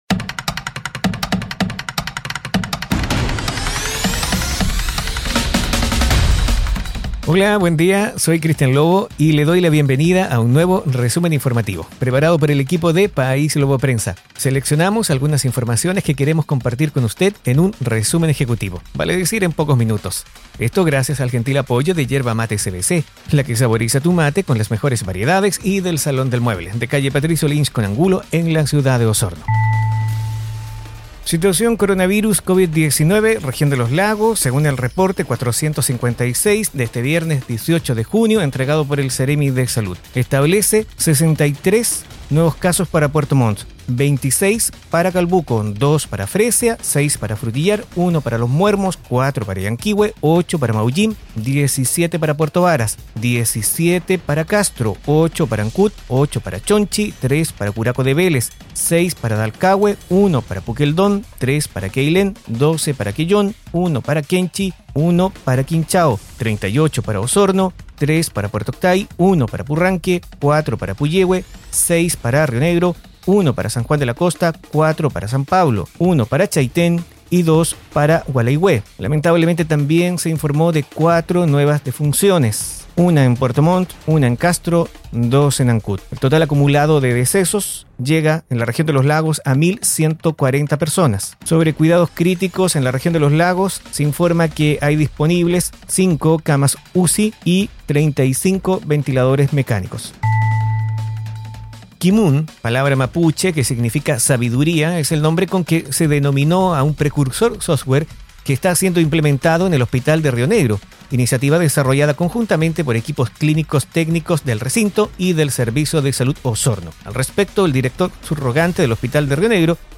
Informaciones y noticias enfocadas en la Región de Los Lagos. Difundido en radios asociadas.